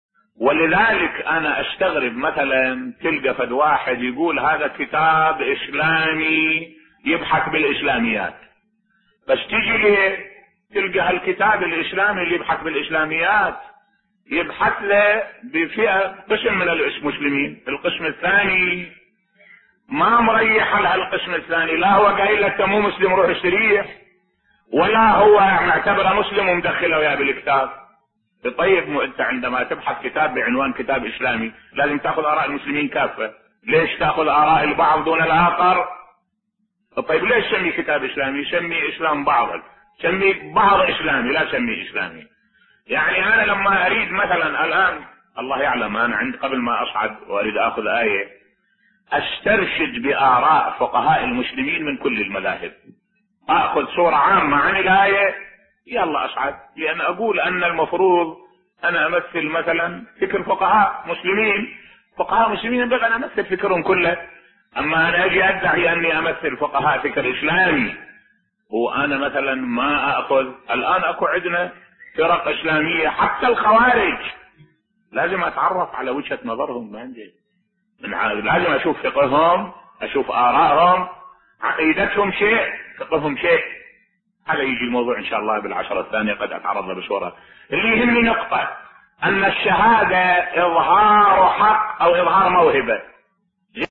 ملف صوتی لا يروق لي أن اصعد المنبر دون أن اطلع على اراء جميع المسلمين بصوت الشيخ الدكتور أحمد الوائلي